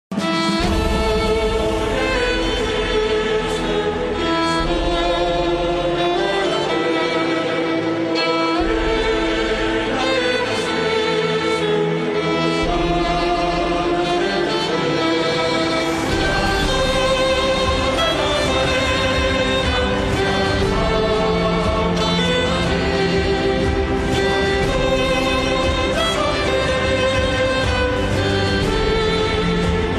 a heroic, powerful and passionate tune